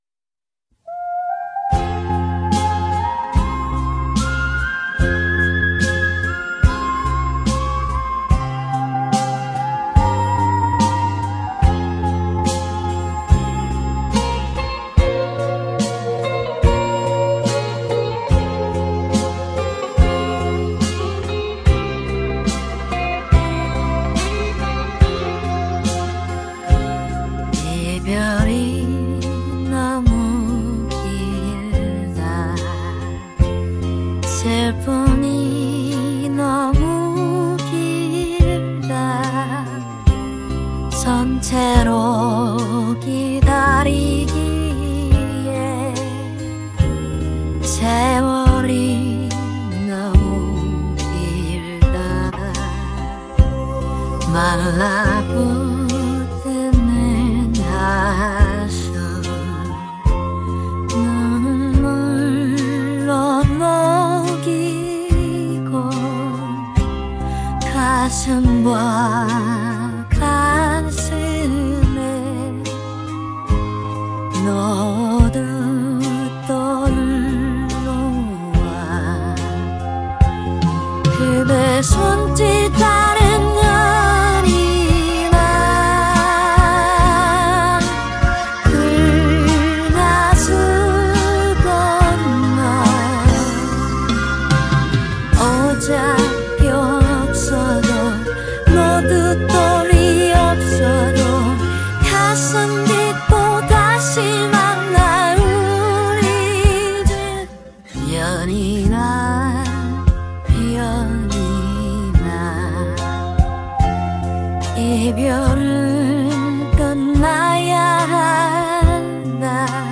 이름하여 ai cover ~
음...확실히 원음과는 차이 있어 보입니다.
가끔 음이 뭉개지는 경우도 있지만, 커버곡과 입력 ai voice의 음질 등에 따라 케바케다 판단해 봅니다.